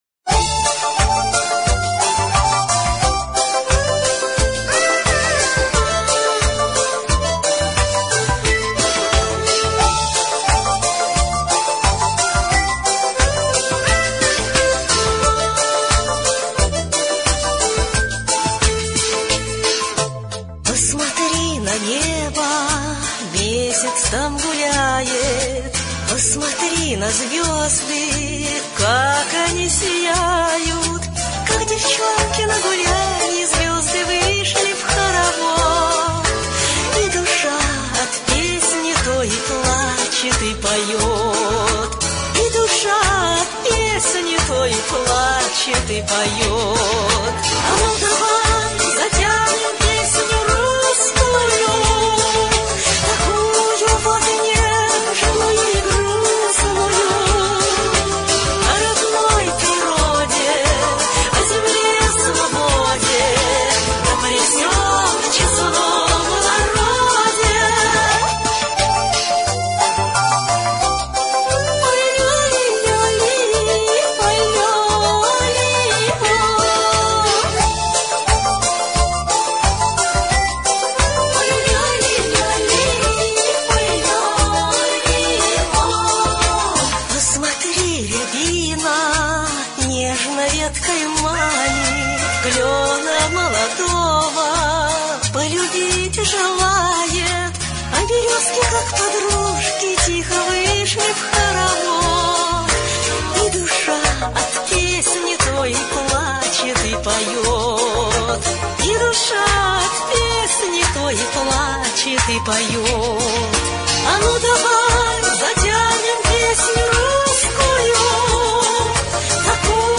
• Категория: Детские песни
народный мотив